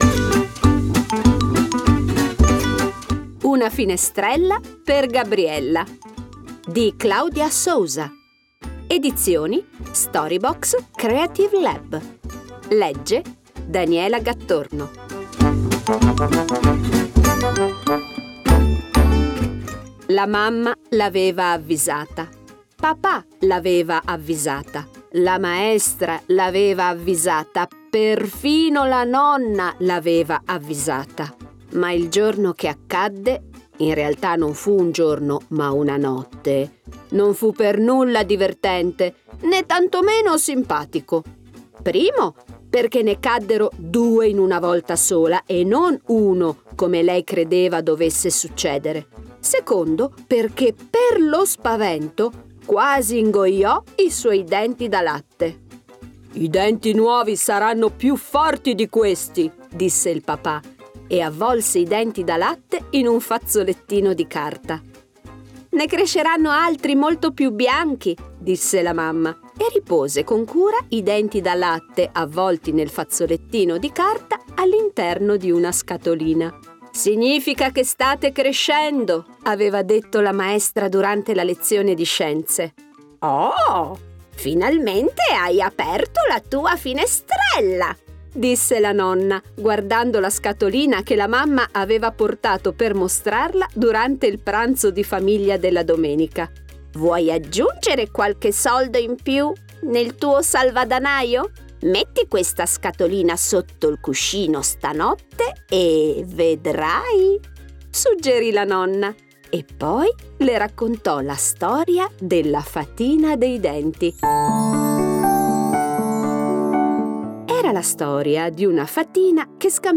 Una finestrella per Gabriella - con tappeto sonoro